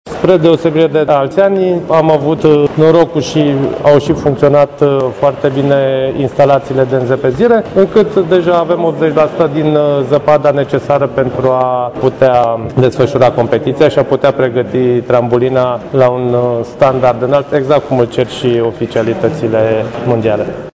Primarul Râșnovului, Liviu Butnariu:
primar-rasnov.mp3